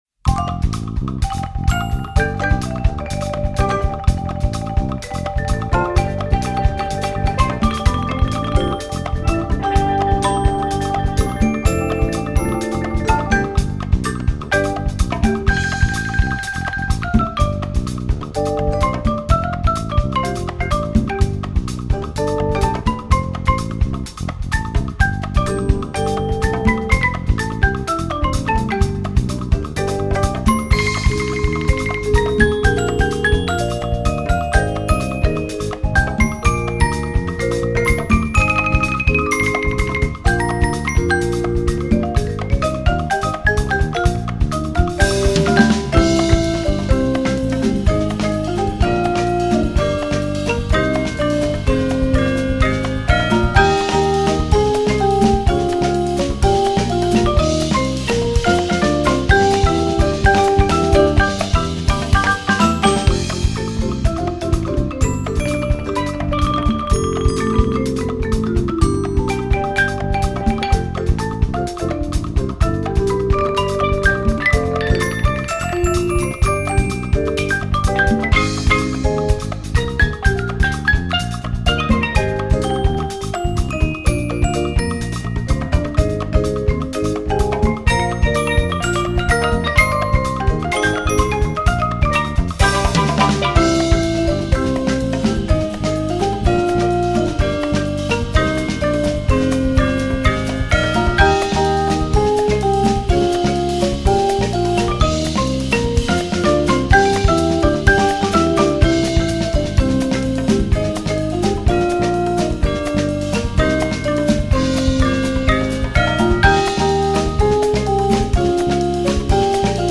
Voicing: 10-11 Percussion